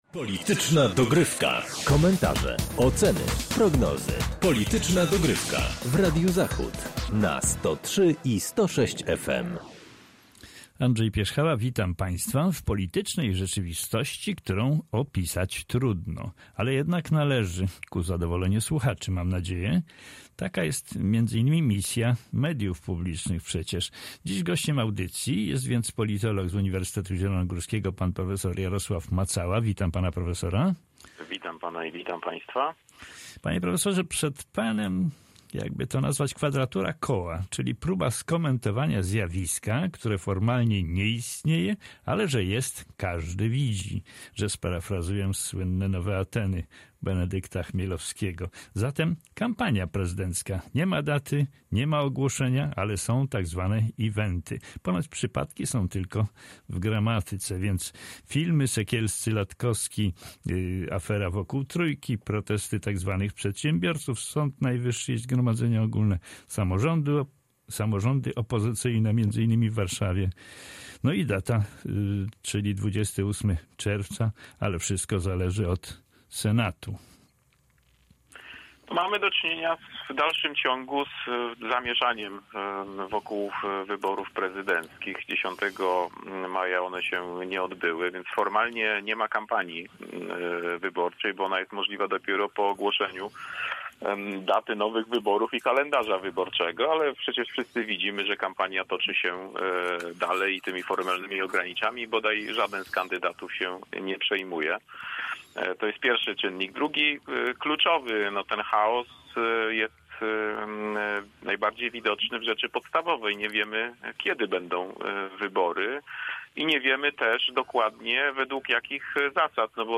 Kolejna rozmowa, porządkująca polityczną wiedzę, spotkanie z doktryną, ideologią, nurtem polityczno-społecznym i myślą polityczną.